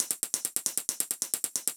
Index of /musicradar/ultimate-hihat-samples/135bpm
UHH_ElectroHatA_135-04.wav